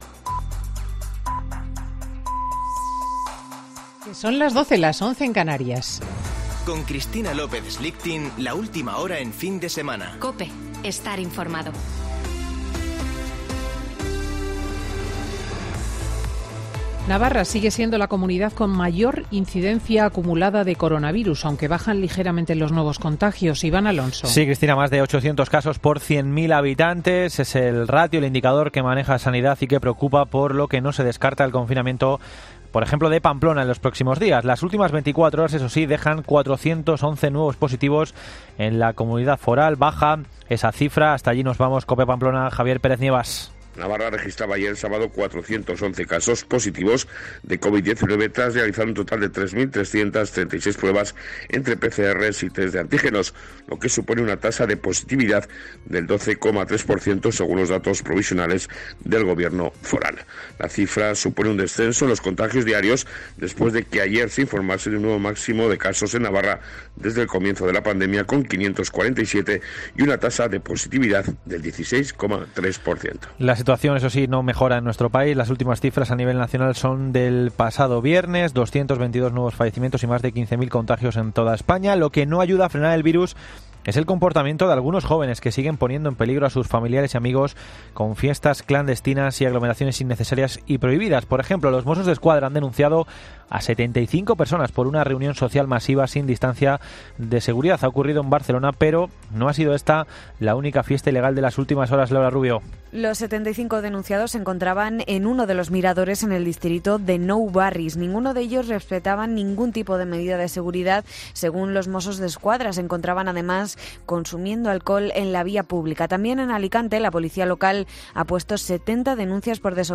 Boletín de noticias de COPE del 18 de Octubre de 2020 a las 12.00 horas